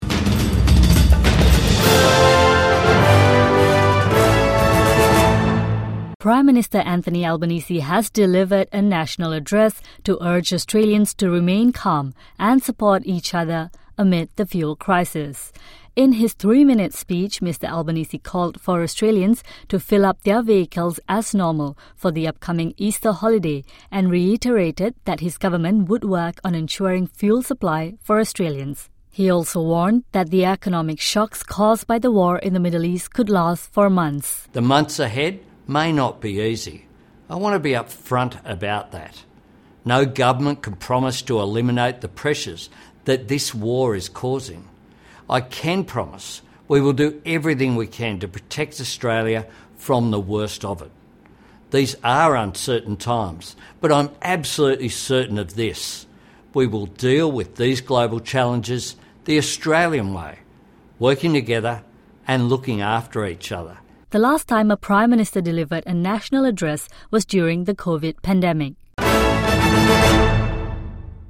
Prime Minister Anthony Albanese delivers national address